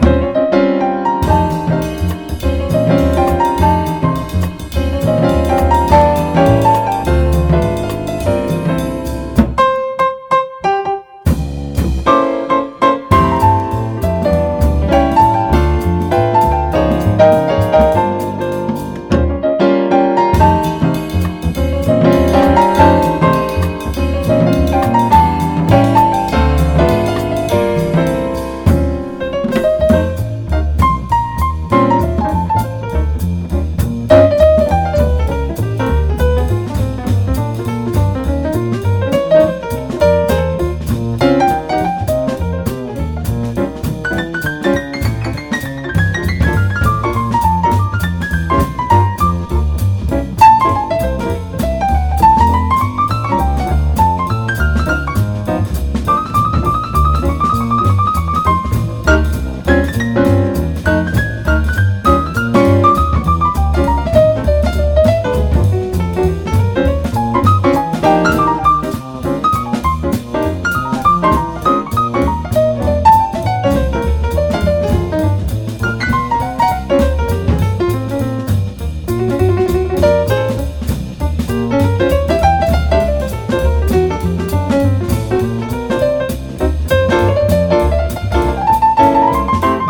JAZZ / MAIN STREAM / MODAL